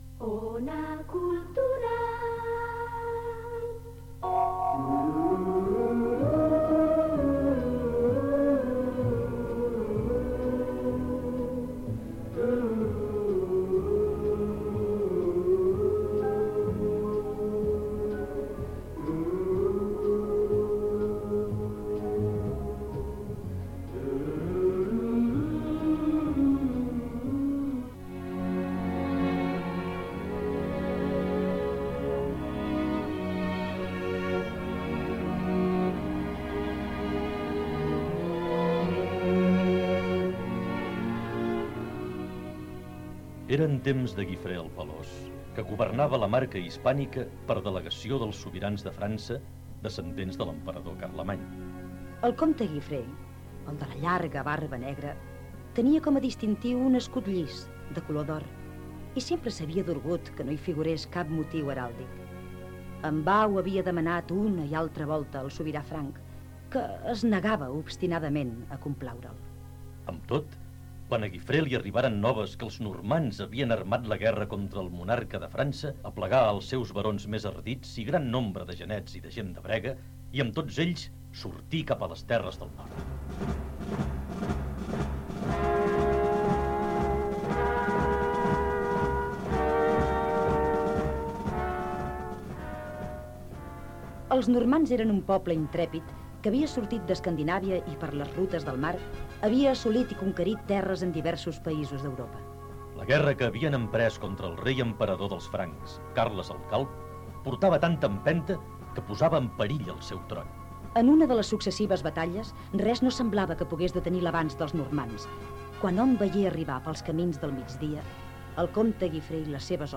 Indicatiu de l'emissora, història del comte Guifré el Pilós.
FM